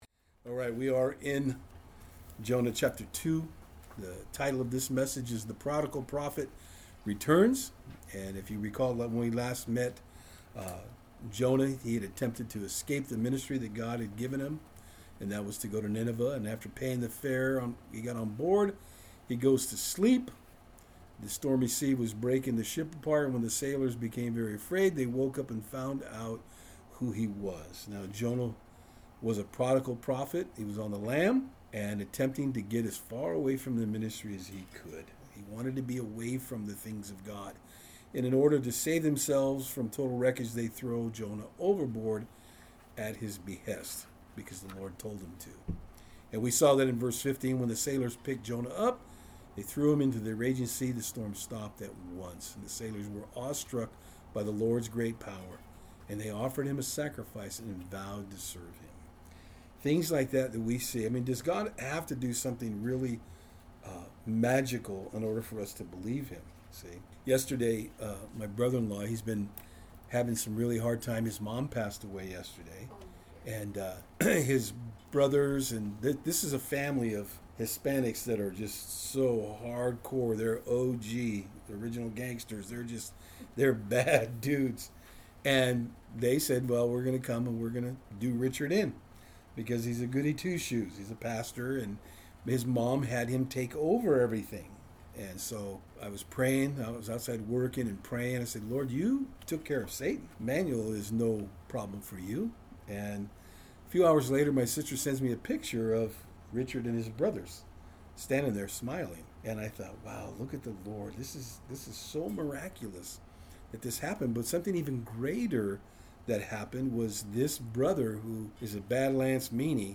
Jonah 1:17- 2:1-10 Service Type: Saturdays on Fort Hill In our study tonight we take a look at the consequences of running form the Lord.